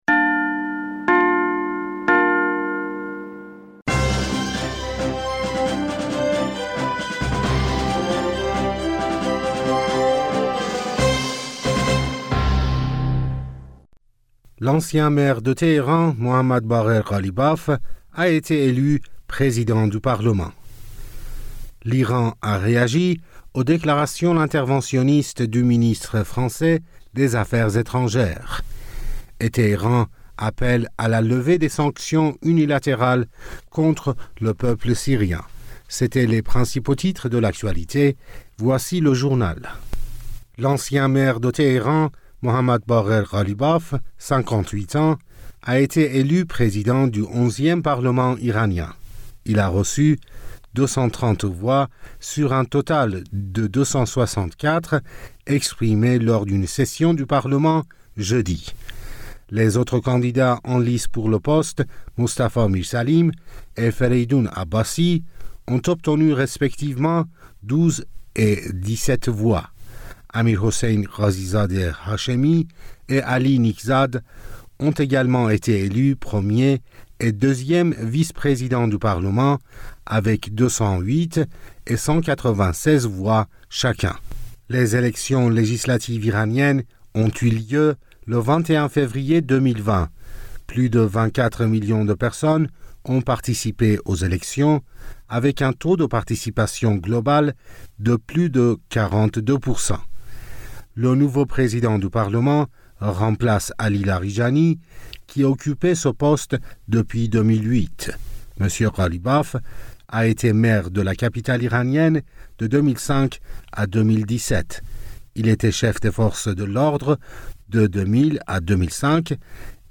bulletin d'information